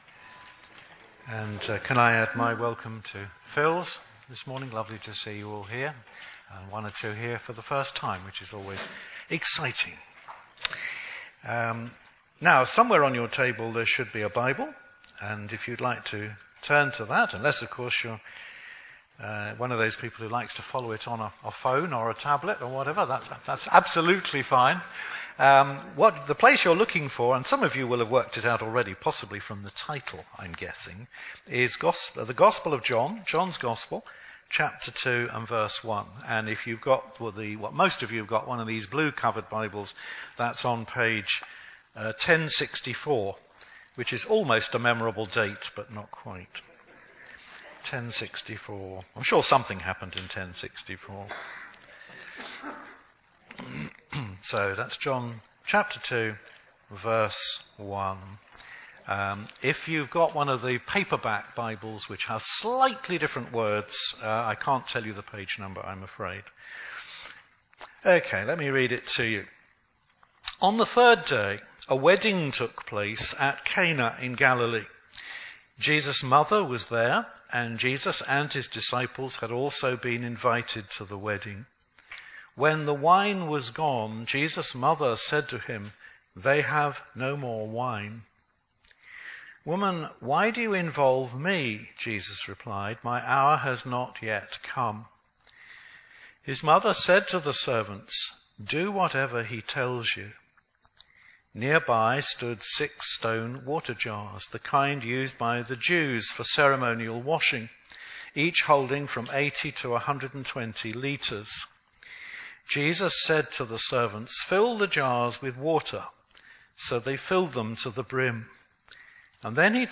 Theme: Whatever he says to you... Sermon Search media library...